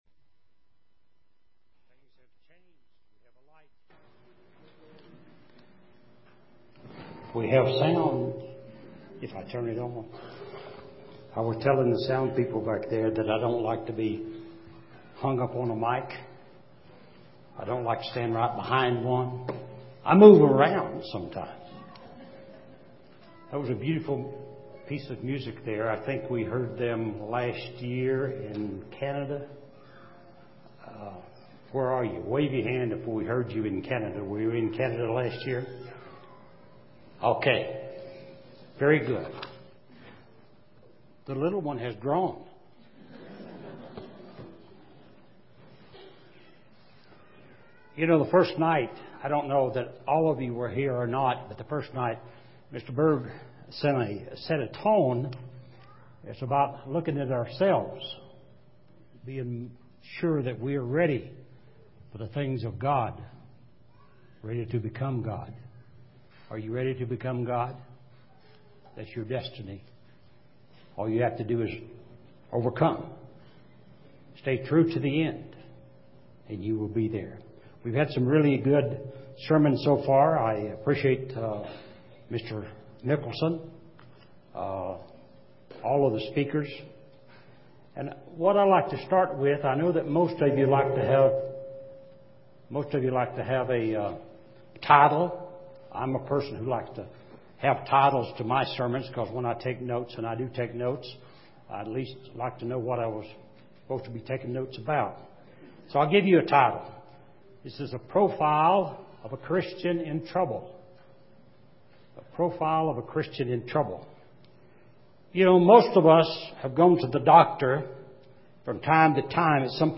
This sermon was given at the Steamboat Springs, Colorado 2011 Feast site.